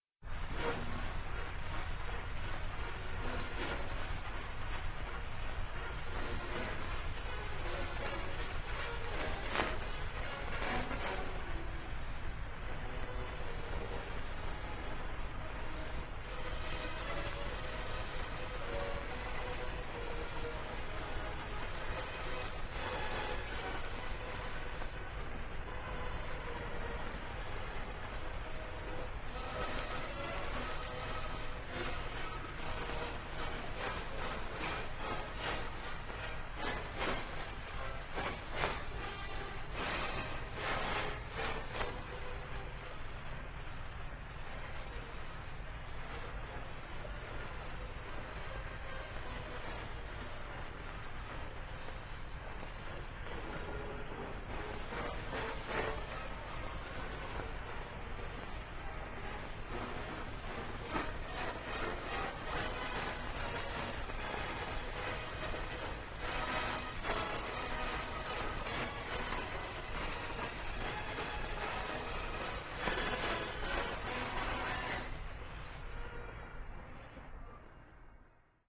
symfonický pochod